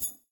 Sfx Getcoin Sound Effect
sfx-getcoin-3.mp3